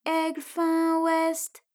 ALYS-DB-003-FRA - Source files of ALYS’ first publicly available French vocal library, initially made for Alter/Ego.